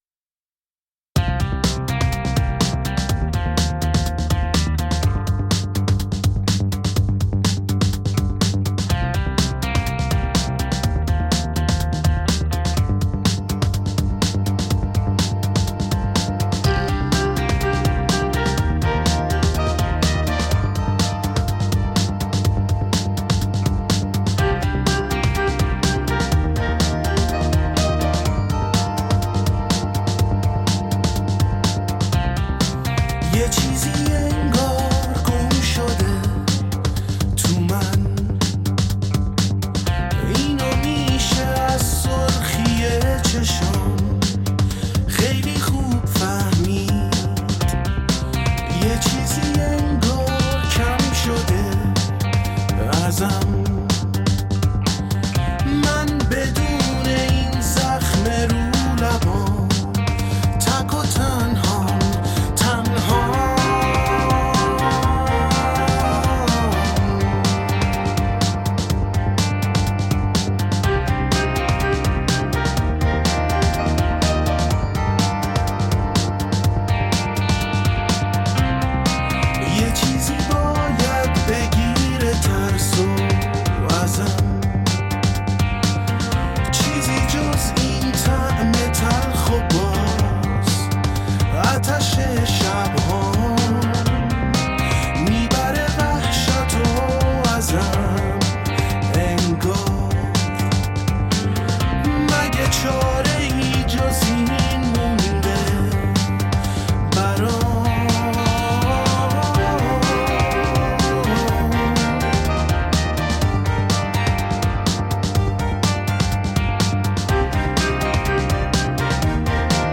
موسیقی راک راک ایرانی